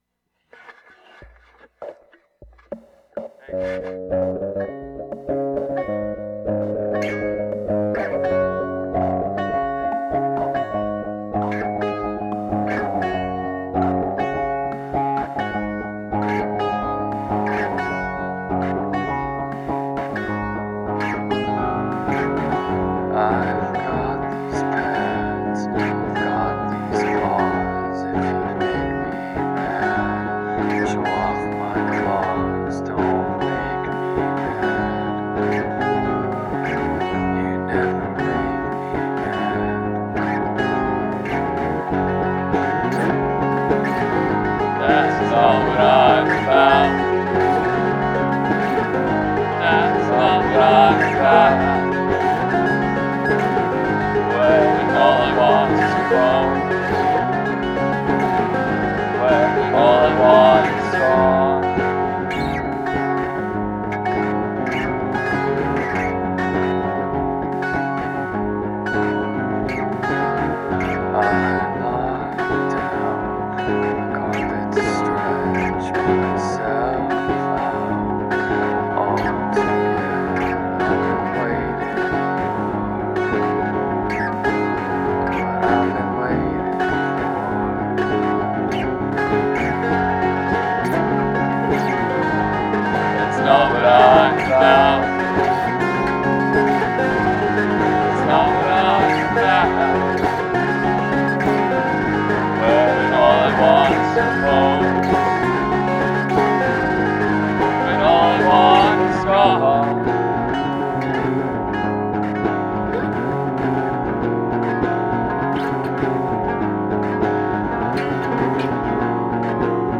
lo-fi psych folk oddities
psych minimalist folk experimental lo-fi folk